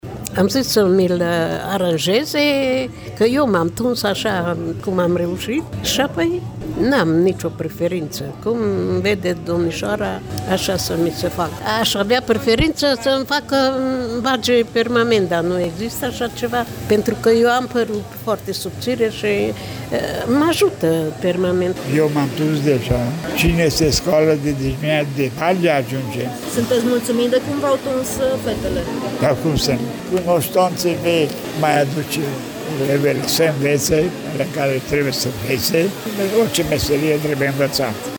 Activitatea a fost organizată astăzi, la Căminul pentru persoane vârstnice din Târgu Mureş, în baza unei colaborări cu AJOM Mureş.
Bunicii spun că aşteaptă cu drag acest moment, care este un prilej de socializare, şi că şi-ar dori să beneficieze în fiecare lună de astfel de servicii: